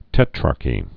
(tĕträrkē, tēträr-) also tet·rar·chate (-kāt, -kĭt)